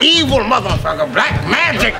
Evil Blackmagic.wav